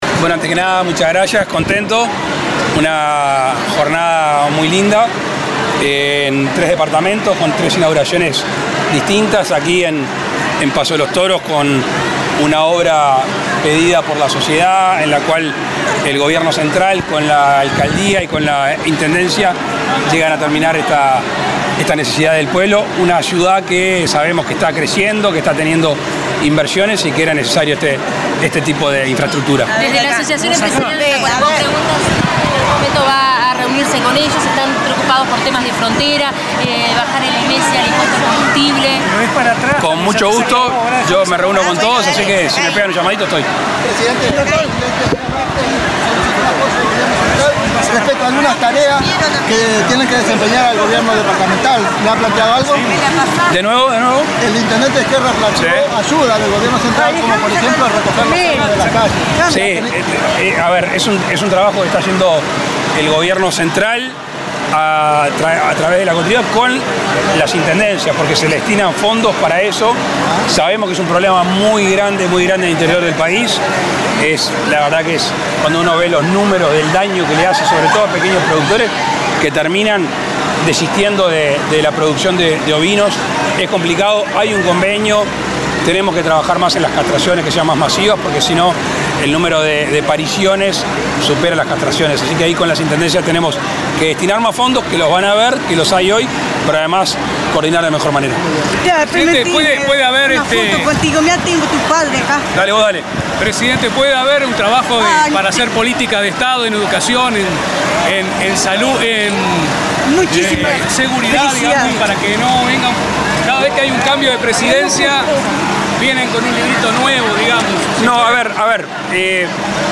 Declaraciones a la prensa del presidente de la República, Luis Lacalle Pou, en Paso de los Toros
Tras el evento, realizó declaraciones a la prensa.